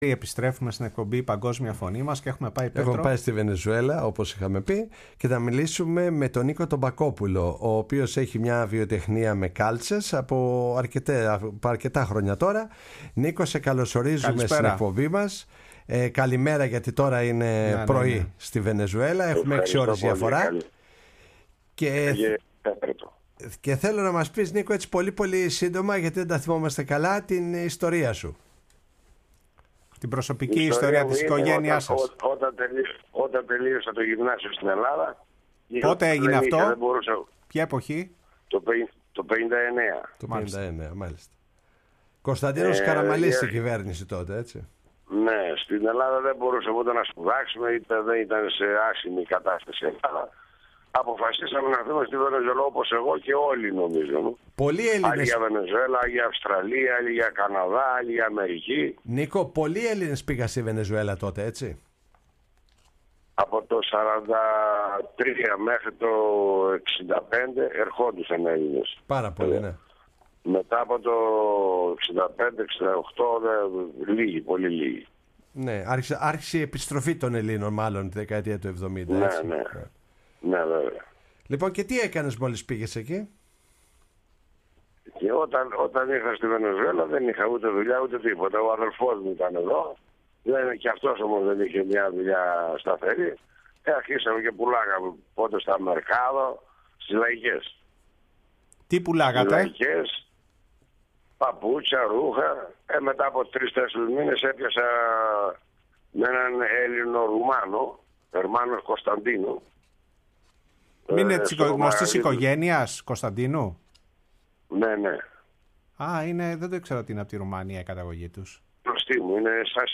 Η Παγκοσμια Φωνη μας Podcast στη Φωνή της Ελλάδας